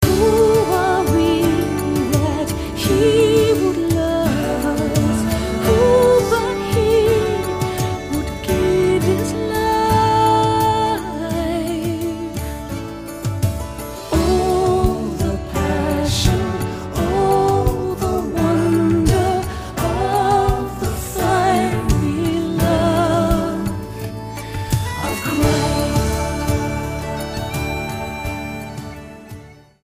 STYLE: Roots/Acoustic
an almost rock-style anthem